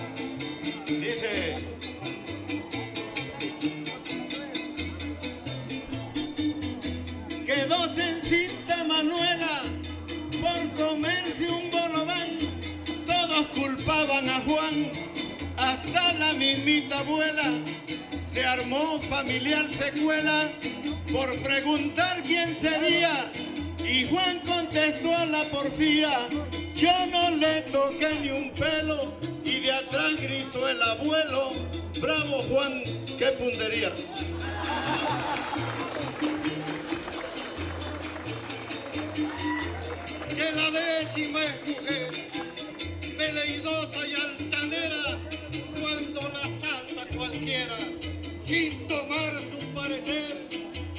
• Siquisirí (Grupo musical)
Concurso Estatal de Fandango